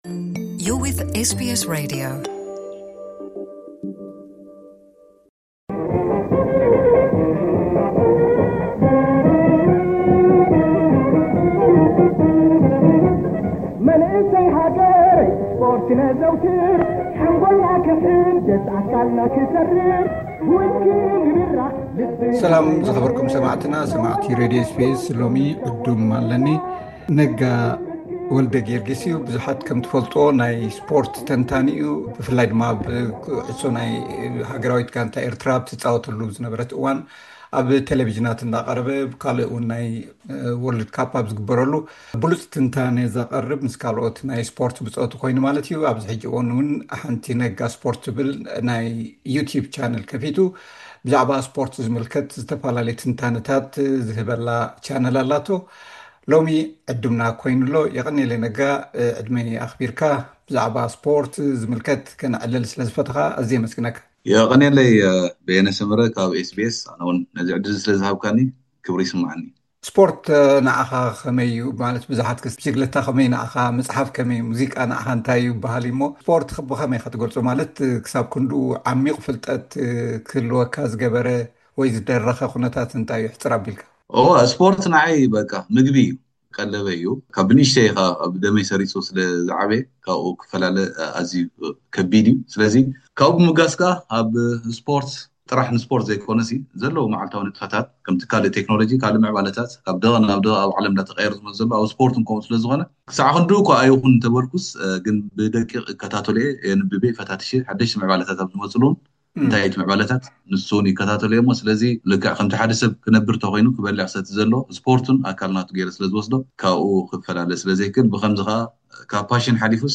ዝተገብረ ቃለ መሕትት ።